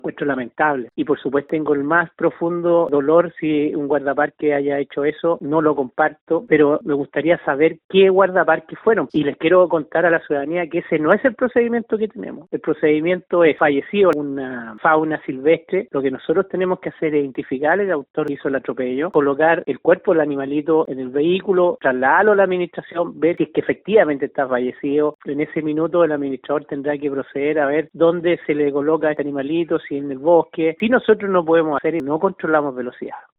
En respuesta a lo anterior, el Director Regional de dicha institución en Los Lagos, Miguel Leiva, lamentó lo sucedido y detalló que ese no es el procedimiento correcto.
cuna-director-conaf.mp3